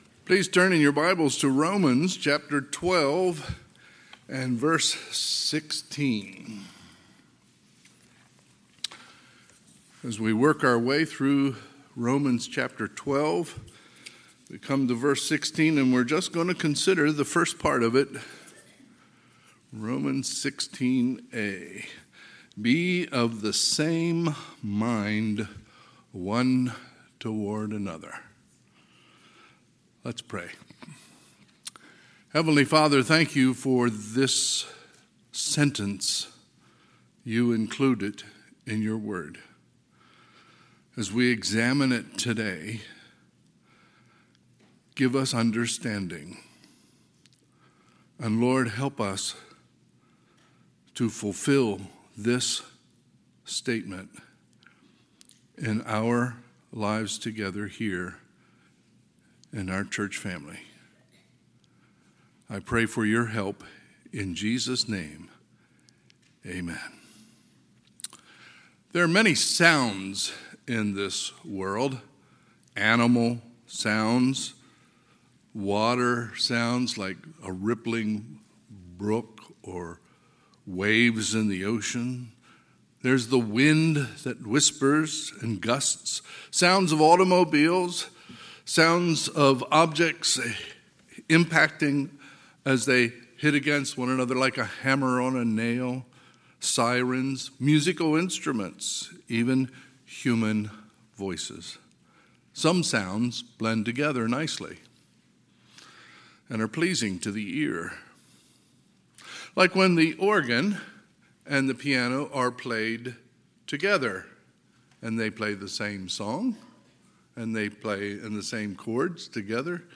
Sunday, August 28, 2022 – Sunday AM
Sermons